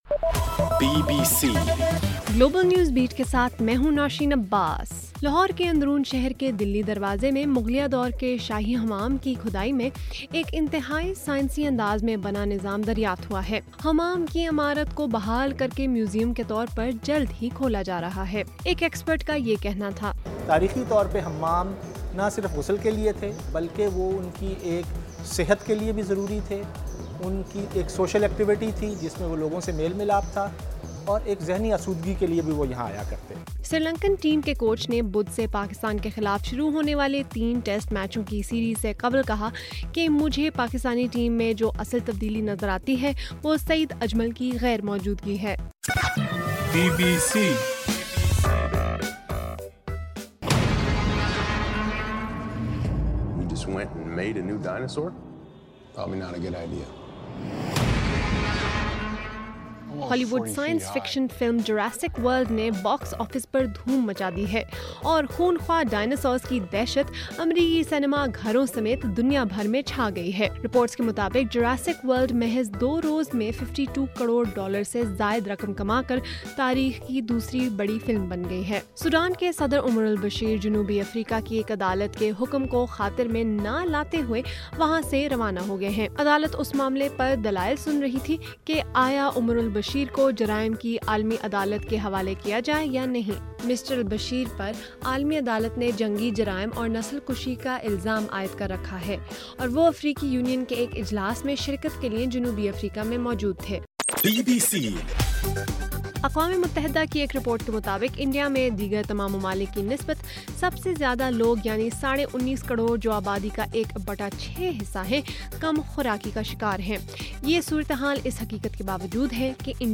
جون 15: رات 11 بجے کا گلوبل نیوز بیٹ بُلیٹن